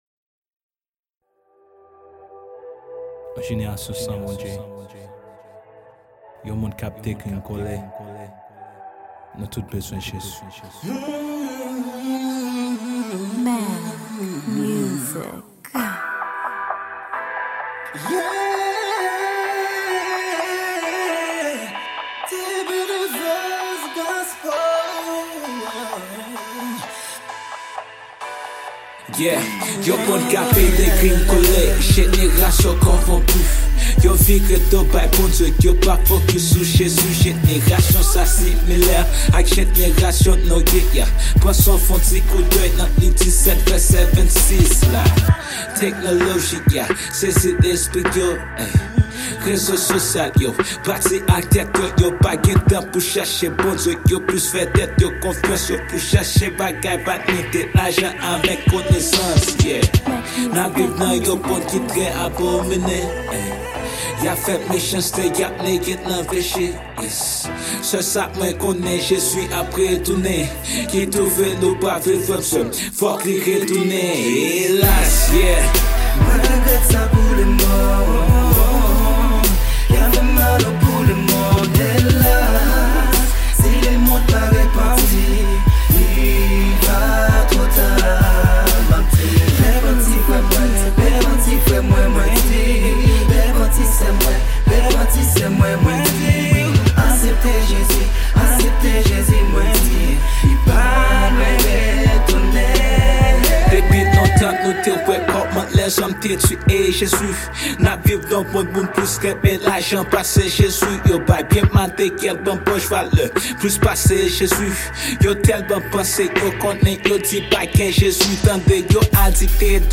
Genre : goespel